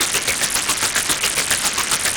Index of /musicradar/rhythmic-inspiration-samples/110bpm
RI_RhythNoise_110-02.wav